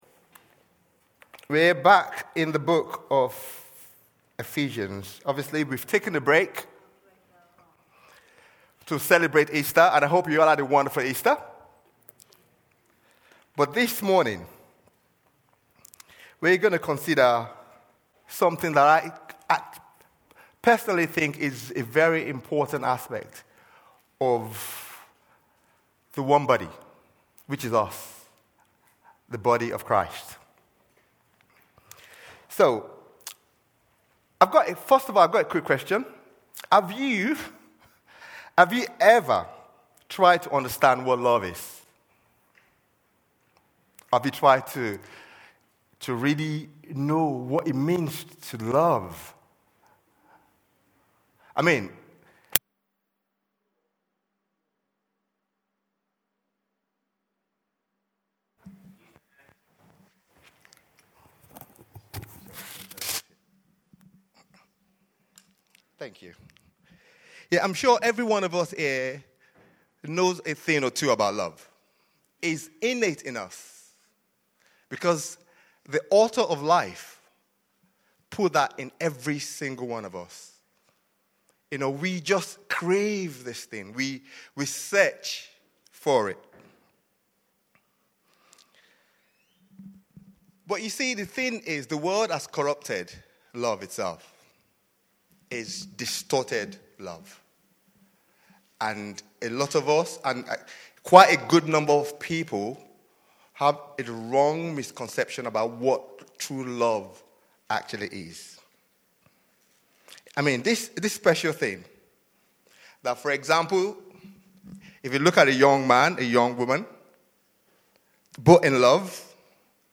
This preach is a reminder that, as believers, we should allow the love of God to be expressed in our lifestyle. It should be the motive and driving force behind all we do, as we have been shown the deepest love by Christ.